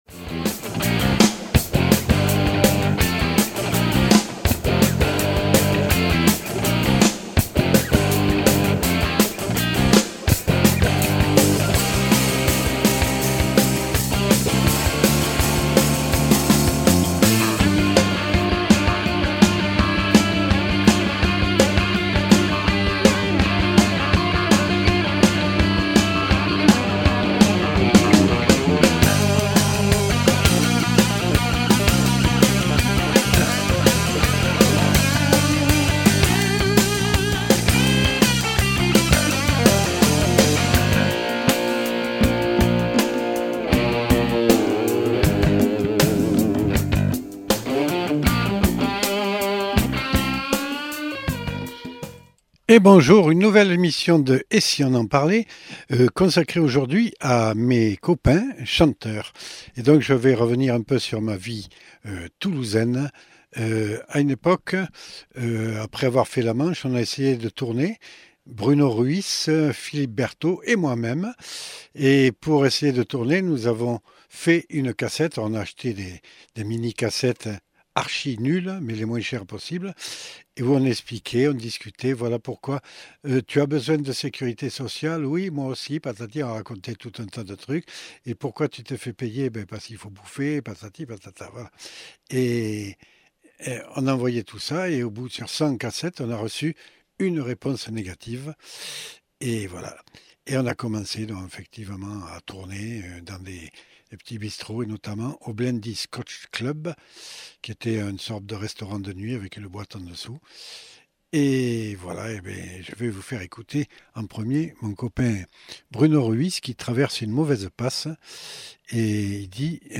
Invité(s) : Mes amis chanteurs Toulousains et autres